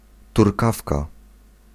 Ääntäminen
Ääntäminen France: IPA: /tuʁ.tə.ʁɛl/ Haettu sana löytyi näillä lähdekielillä: ranska Käännös Ääninäyte Substantiivit 1. synogarlica turecka 2. sierpówka Muut/tuntemattomat 3. turkawka {f} Suku: f .